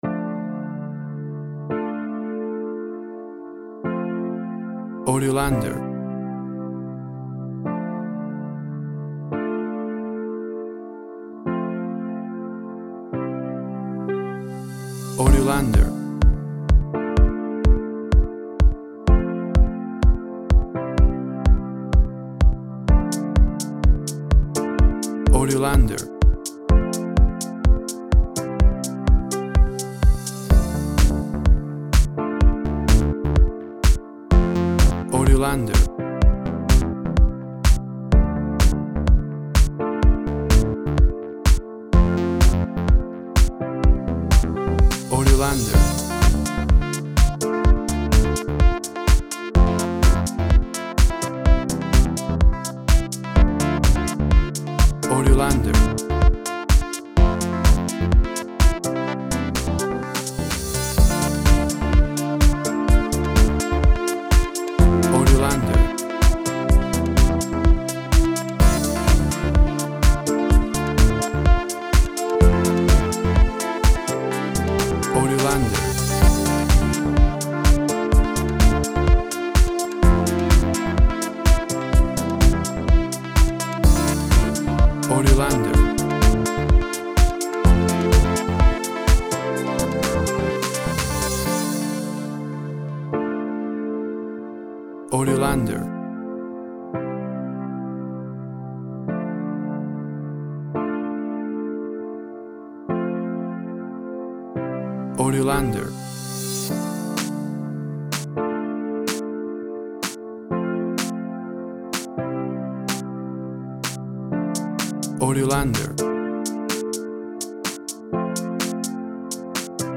Warm Groove House music. Upbeat, groovy, uptempo and funky!
Tempo (BPM) 127